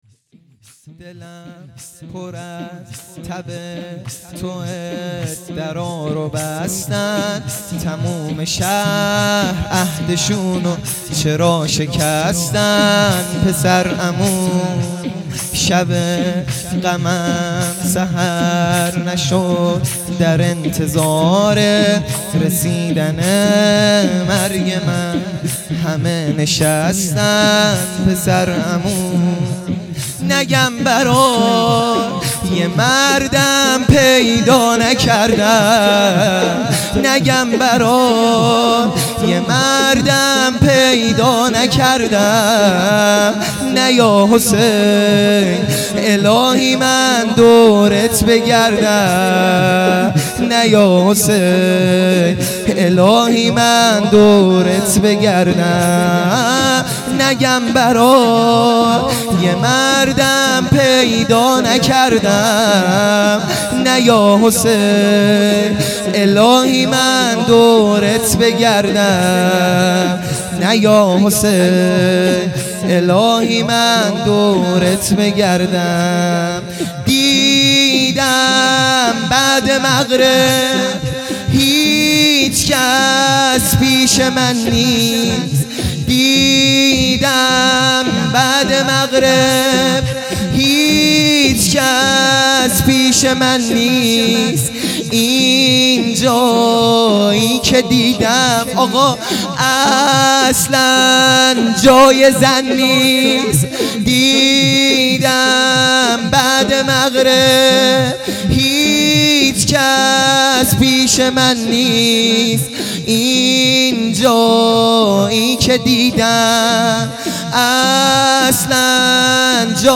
زمینه | برگرد جان زینب